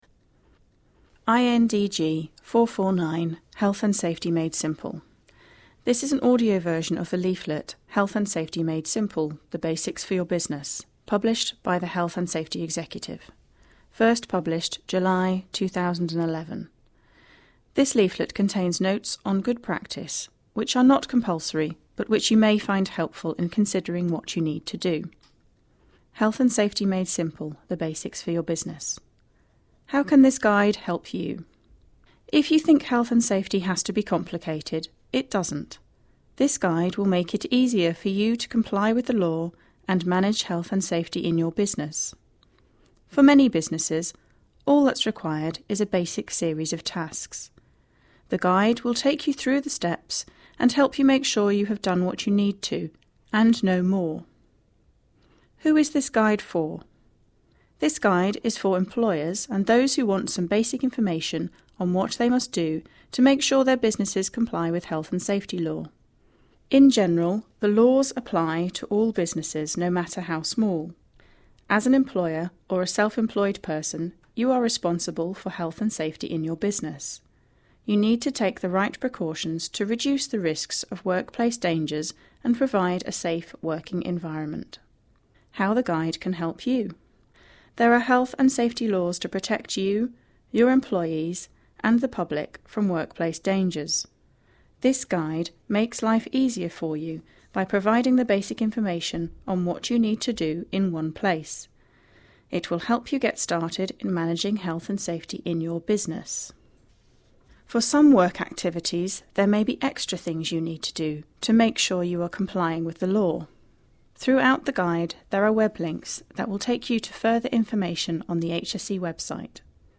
Also, here is a very useful 'Talking leaflet' that you can listen to called 'Health & Safety made simple'.